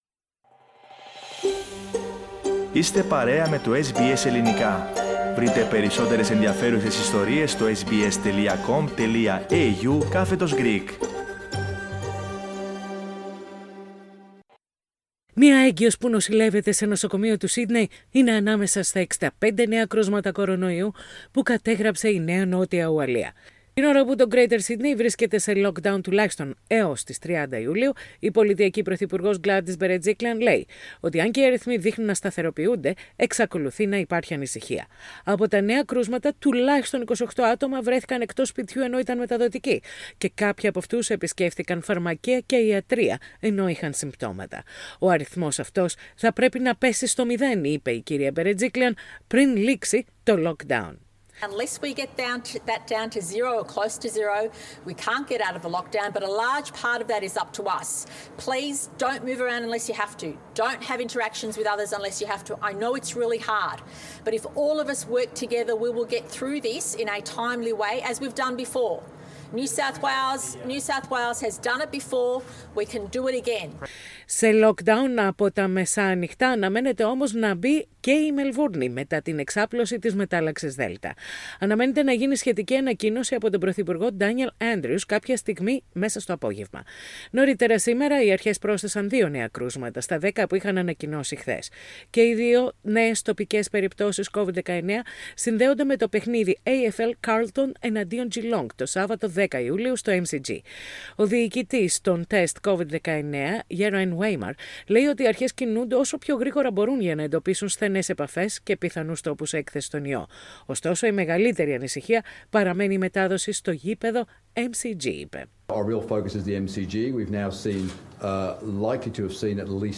Ειδήσεις στα Ελληνικά - Πέμπτη 15.7.21